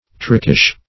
Search Result for " trickish" : The Collaborative International Dictionary of English v.0.48: Trickish \Trick"ish\, a. Given to tricks; artful in making bargains; given to deception and cheating; knavish.